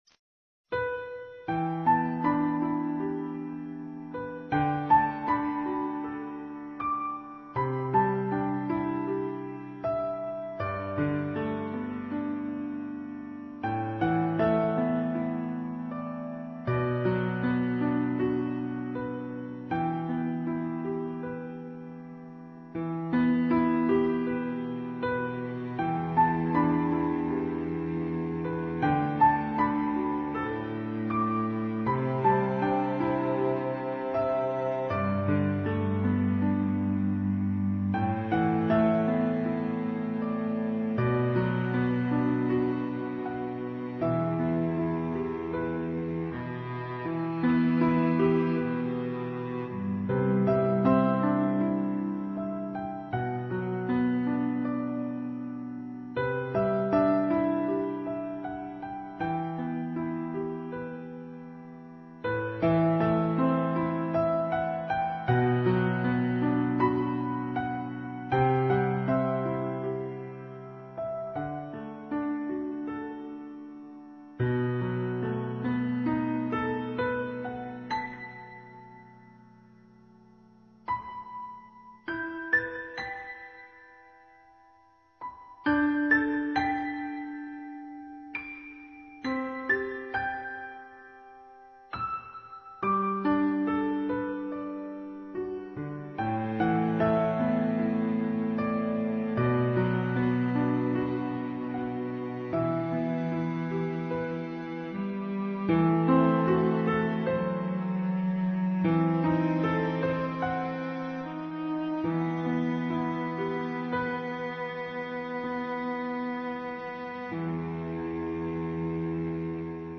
天籁钢琴 你是第5806个围观者 0条评论 供稿者： 标签：